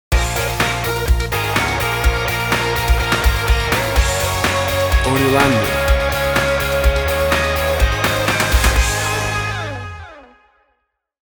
WAV Sample Rate: 24-Bit stereo, 44.1 kHz
Tempo (BPM): 125